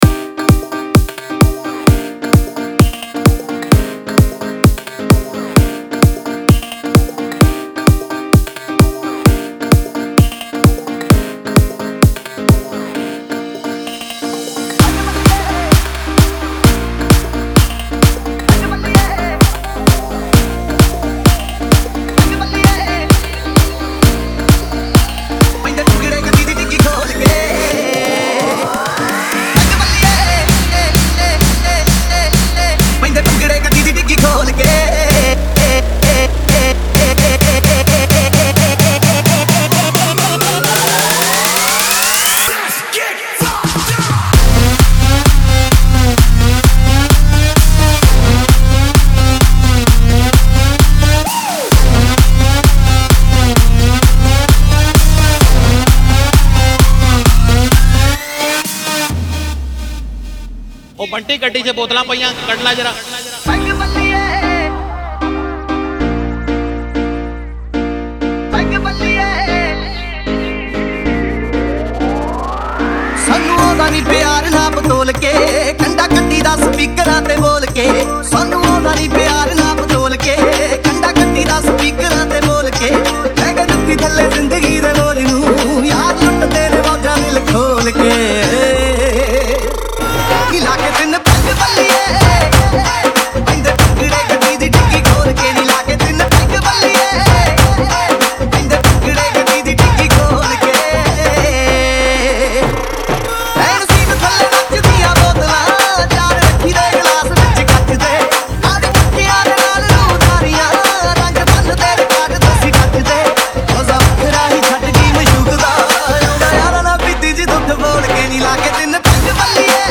2020 Punjabi Mp3 Songs
DJ Remix